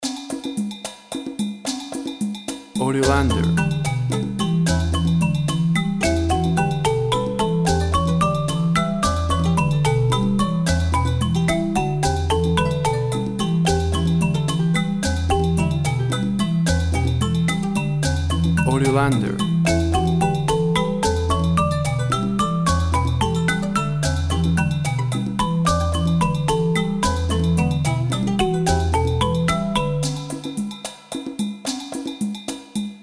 Percussions and marimbas evoking Africa.
Tempo (BPM) 100